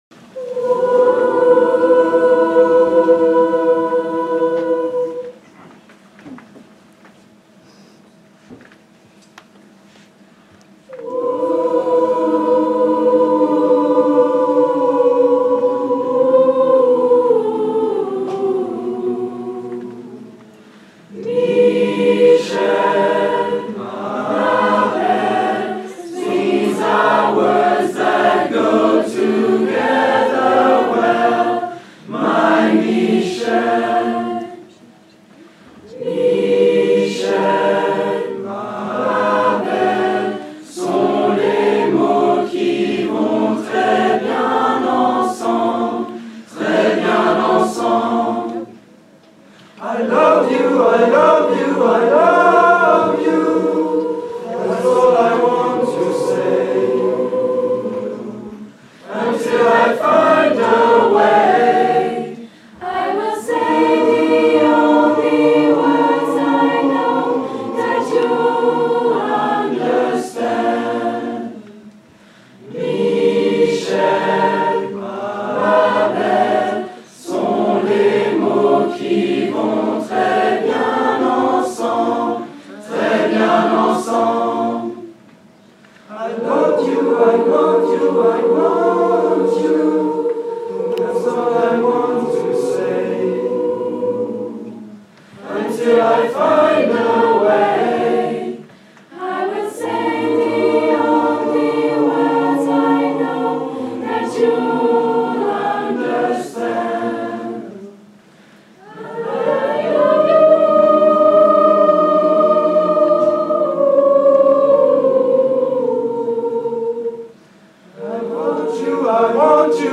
les options musique en concert
Jeudi 15 décembre, les élèves des 3 niveaux ont fait une prestation devant un public nombreux.
chant-Michelle.mp3